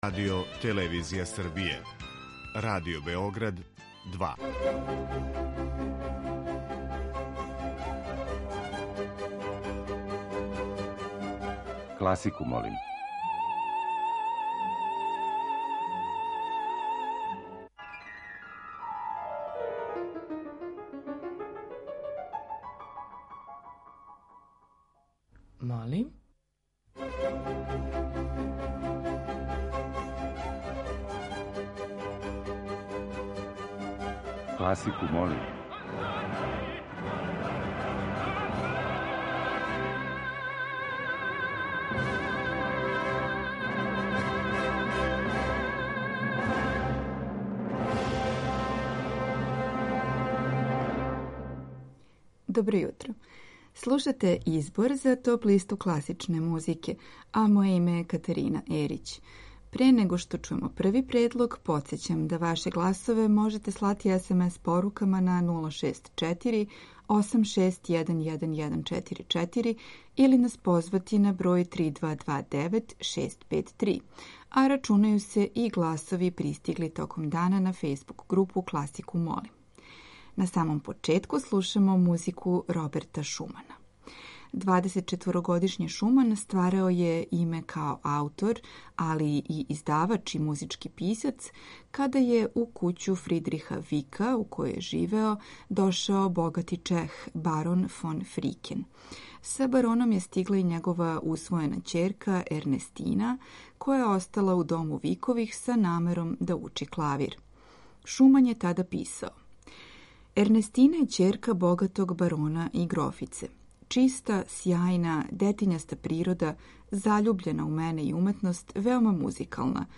Дела Делиба, Адама, Офенбаха и Равела дочараће овонедељну тему.